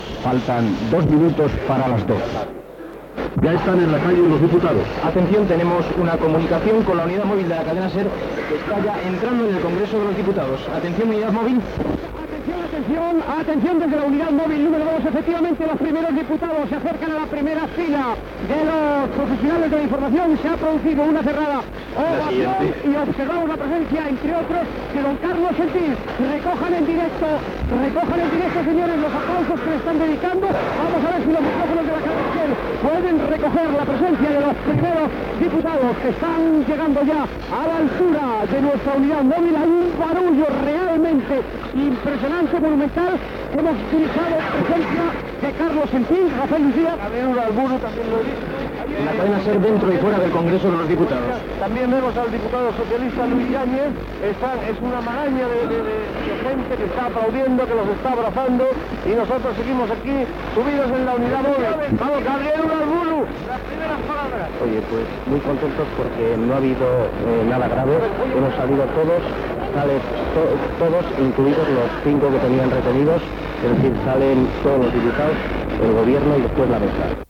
Informatiu
Extret del casset "La SER informa de pleno" publicat per la Cadena SER